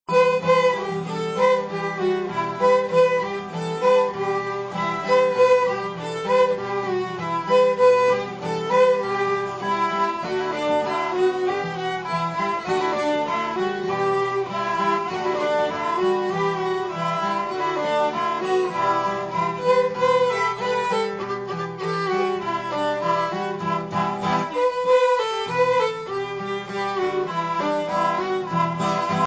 Fiddles
Guitar